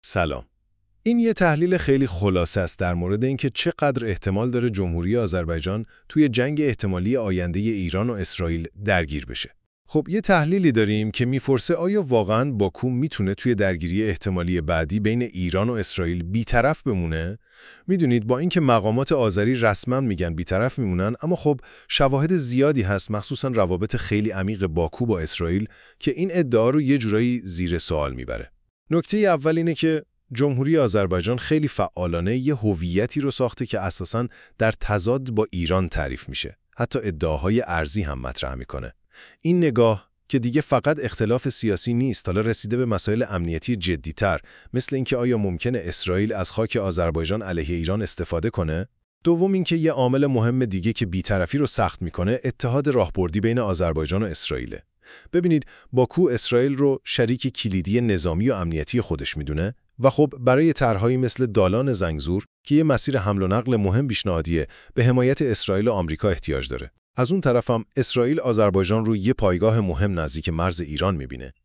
💡 در گویندگی این برنامه از هوش مصنوعی استفاده شده و پیشاپیش به‌خاطر برخی اشتباهات اعرابی در تلفظ‌ها عذرخواهی می‌کنیم.